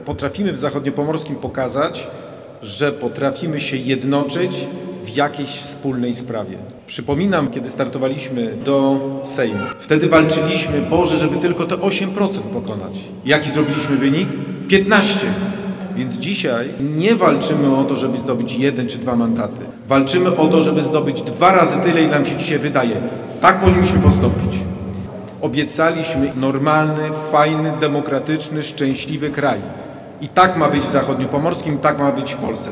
Podczas dzisiejszej konwencji, Trzecia Droga, czyli koalicja Polskiego Stronnictwa Ludowego i Polski 2050 Szymona Hołowni przedstawiła swoich kandydatów na radnych Sejmiku Województwa Zachodniopomorskiego.
SZCZ-Rudawski-KonwencjaTD.mp3